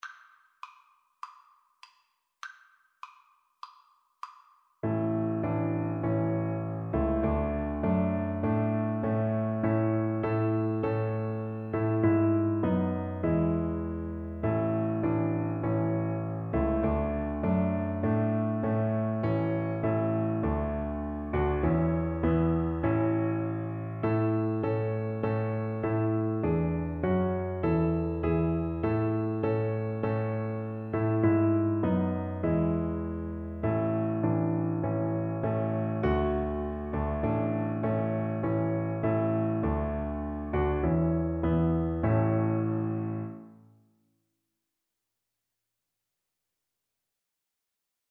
4/4 (View more 4/4 Music)
E5-E6
Classical (View more Classical Violin Music)